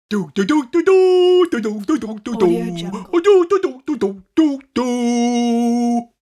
6VYUXP4-cartoon-goofy-character-singing-1.mp3